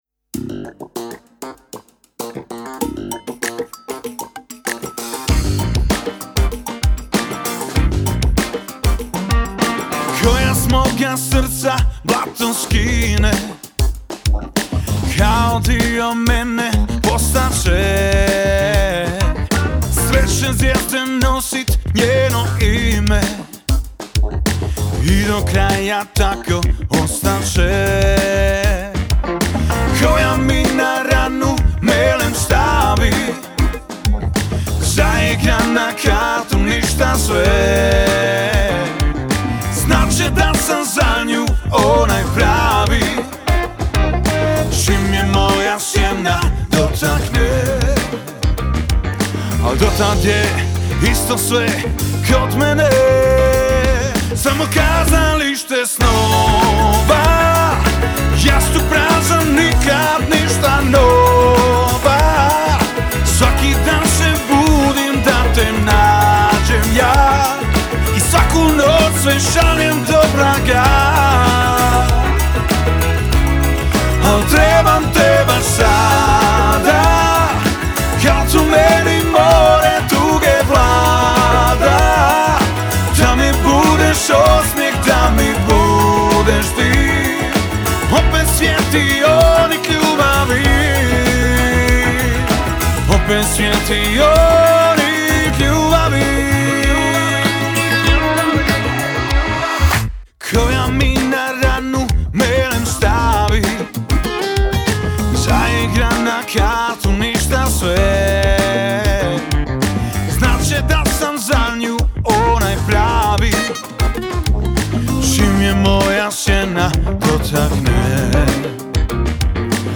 Funky Pop